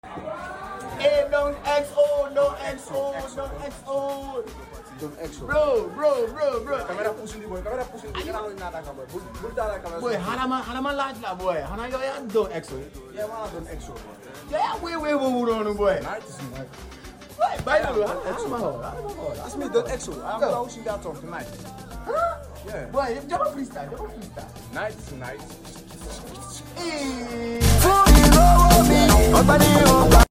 a crazy Amapiano song